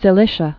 (sĭ-lĭshə)